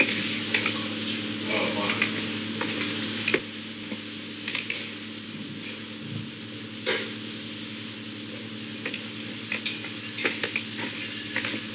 Secret White House Tapes
Conversation No. 422-11
Location: Executive Office Building
The President met with an unknown man.